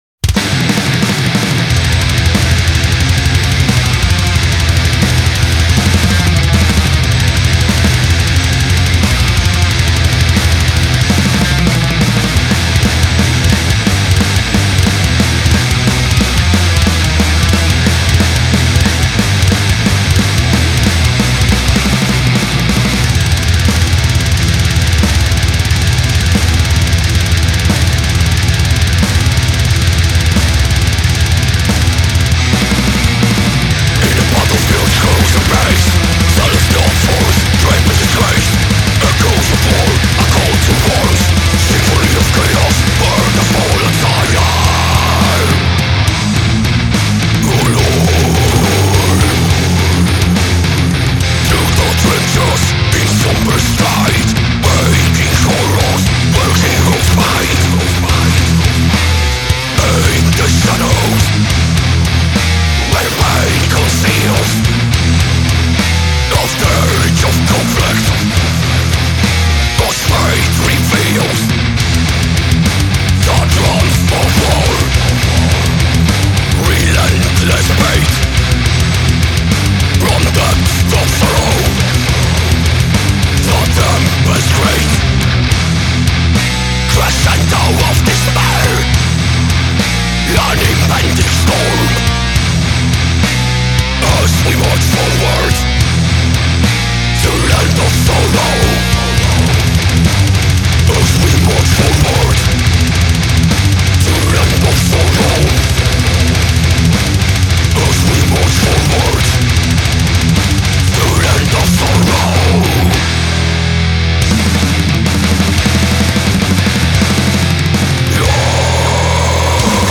Thrash – Death metal